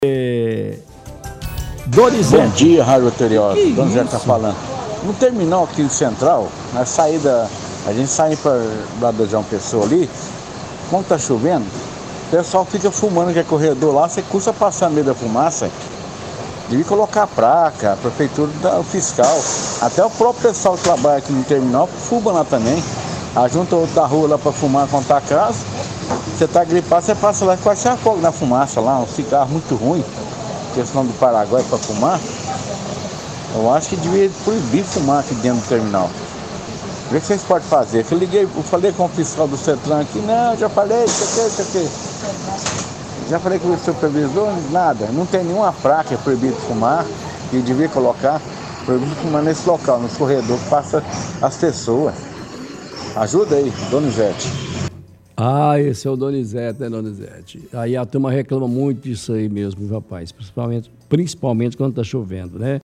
– Ouvinte reclama que na saída do terminal central e também onde os ônibus entra pelo centro, principalmente quando está chovendo o povo fica fumando dificultado a respiração no terminal.